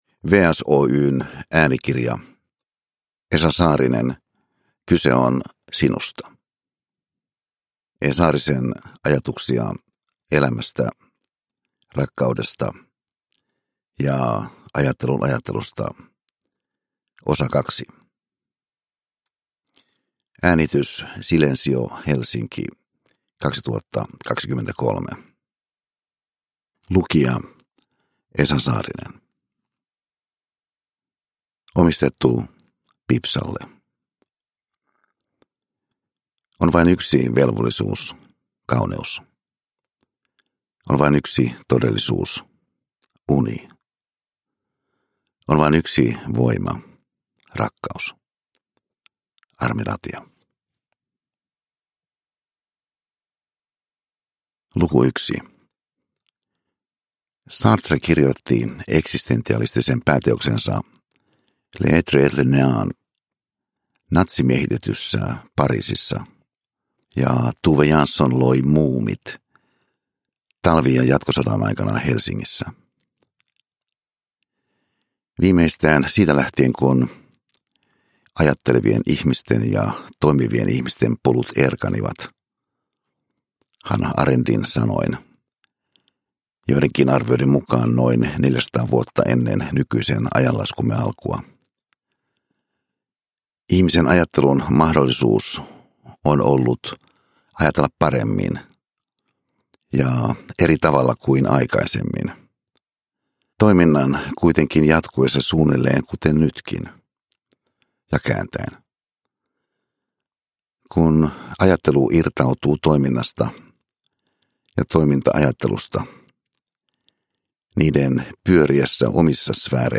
Uppläsare: Esa Saarinen